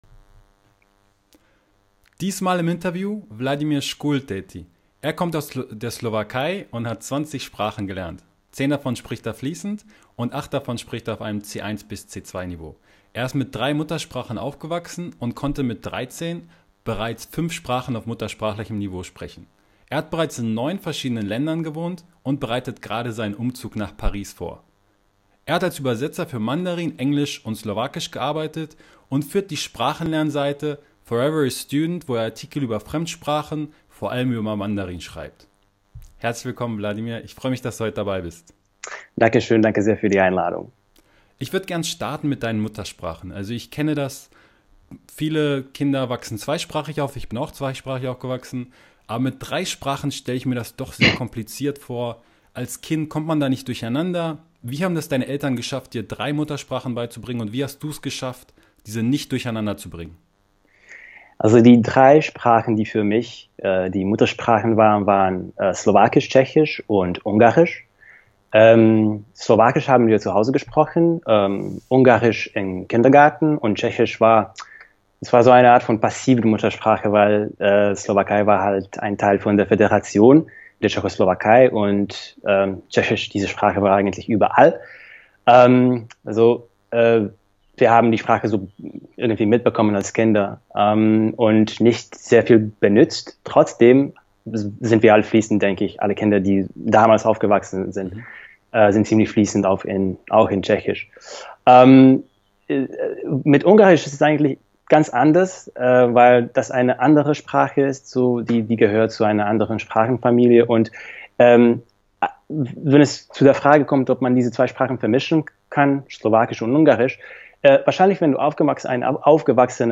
Chinesisch lernen – Interview